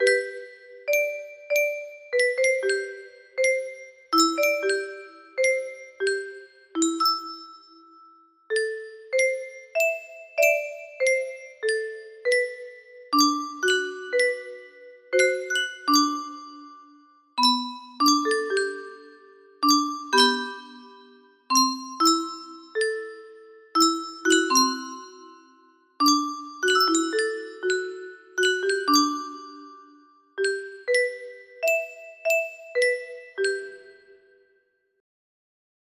Unknown Artist - Untitled music box melody